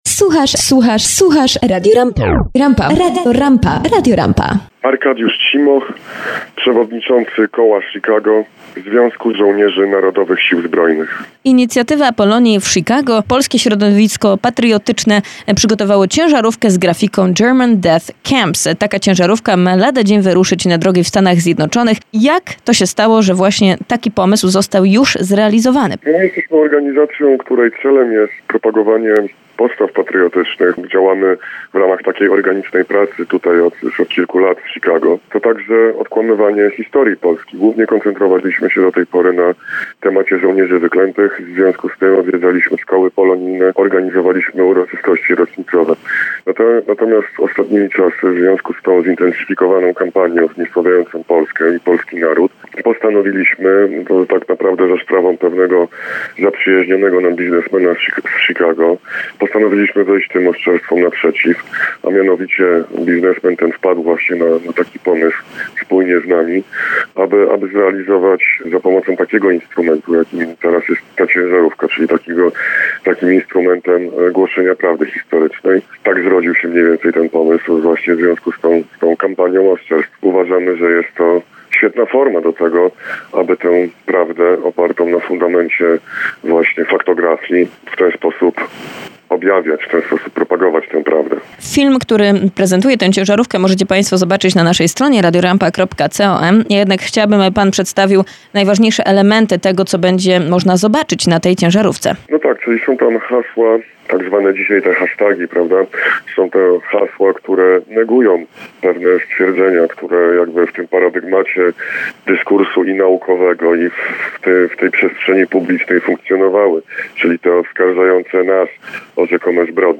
Ciężarówka "German Death Camps" już na drogach amerykańskich - wywiad z jednym z pomysłodawców w Radio RAMPA - RAMPA TV